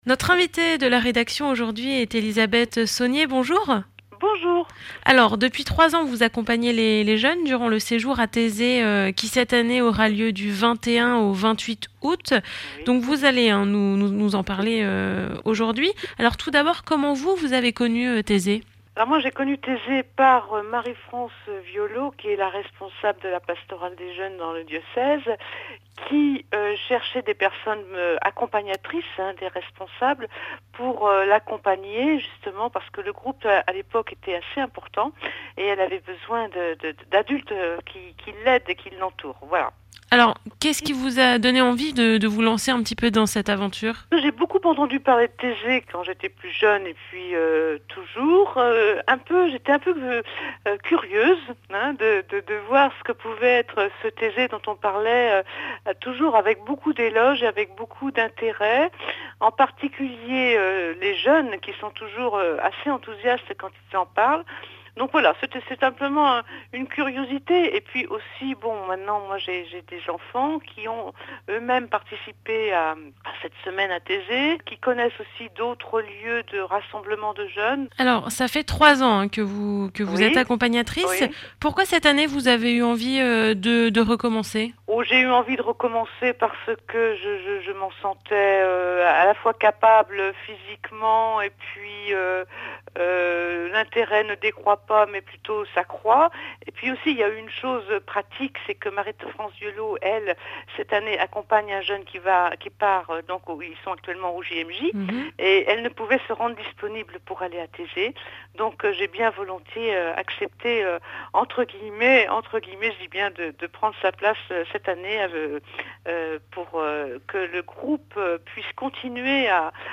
Les podcasts Interview - Page 136 sur 174 - La FRAP